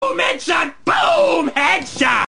Play, download and share Bomhead original sound button!!!!
boom-headshot-mp3cut.mp3